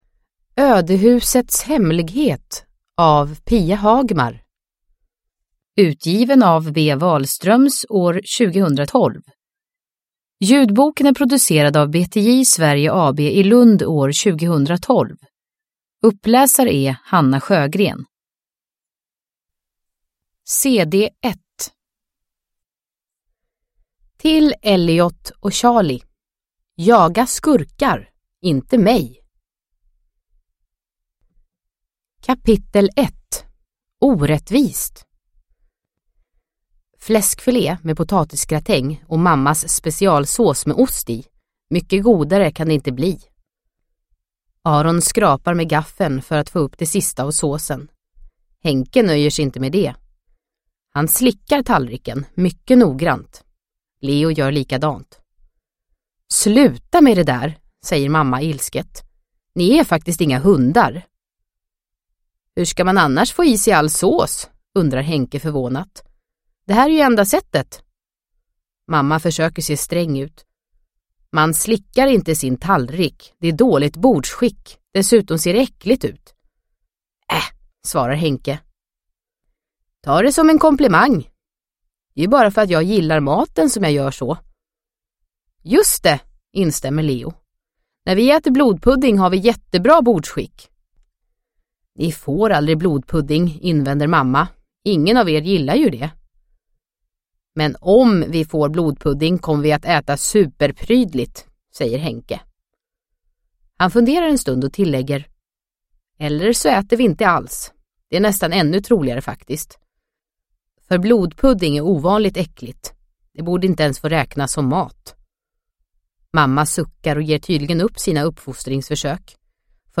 Ödehusets hemlighet – Ljudbok – Laddas ner